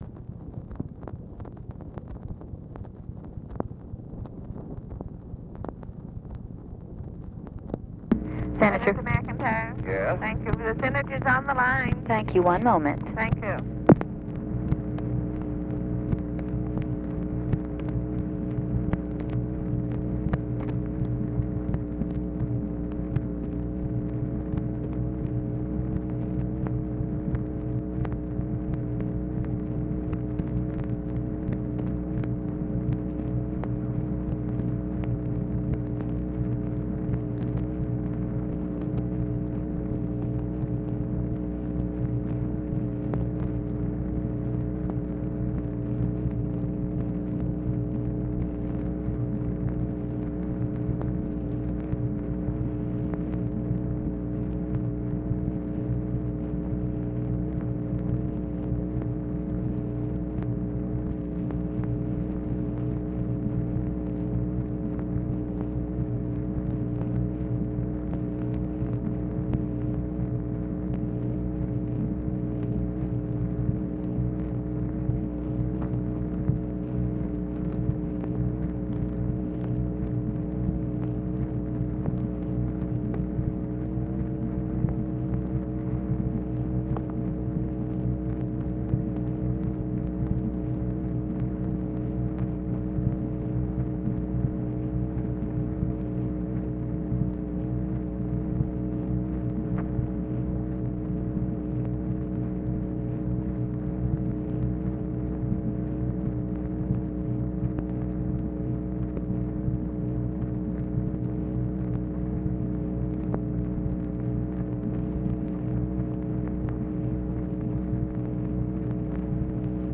Conversation with THOMAS MCINTYRE, November 6, 1964
Secret White House Tapes